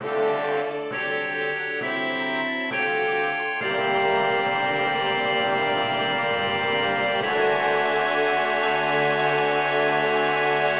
Full score of the "Lacrimosa" from Mozart's Requiem.